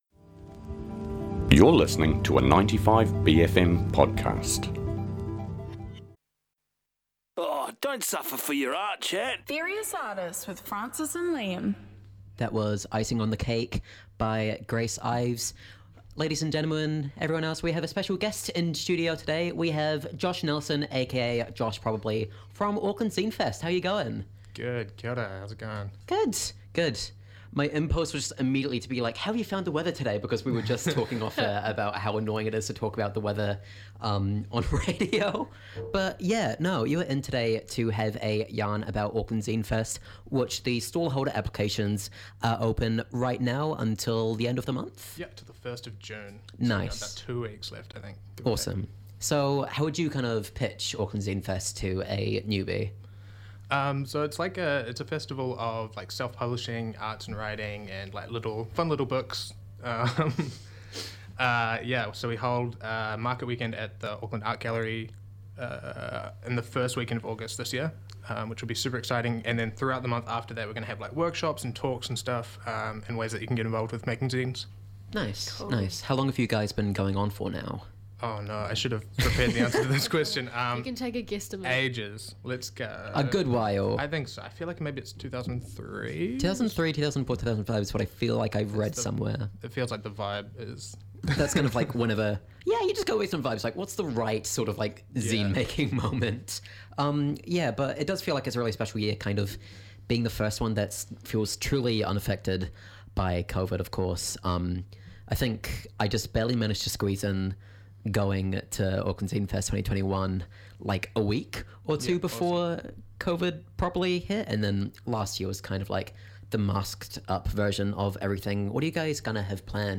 from the Auckland Zine Fest team up to the studio to chat about the market, the plans for this year, and how people can get involved.